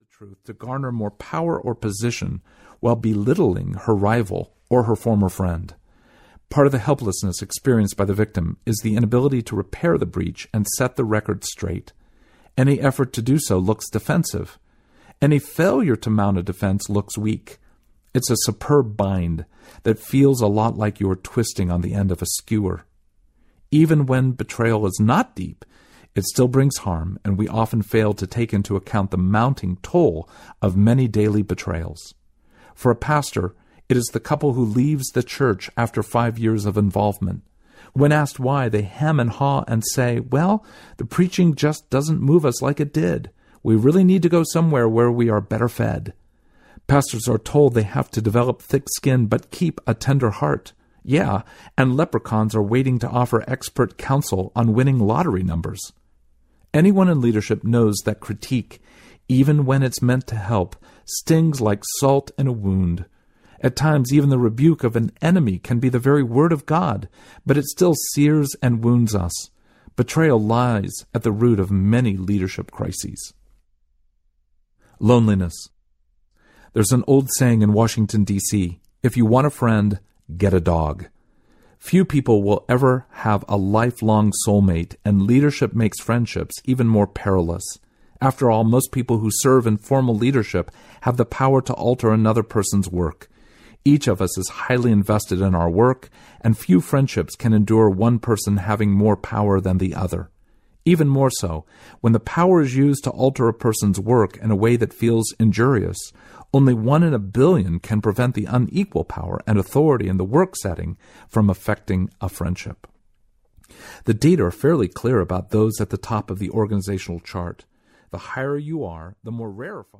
Leading With a Limp Audiobook